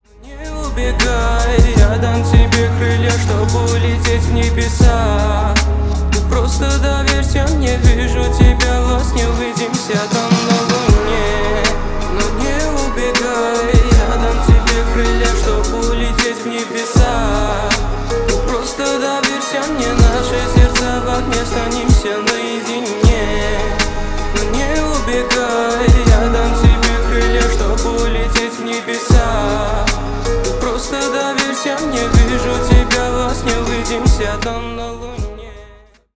Поп Музыка
спокойные
тихие